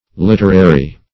Literary \Lit"er*a*ry\ (l[i^]t"[~e]r*[asl]*r[y^]), a. [L.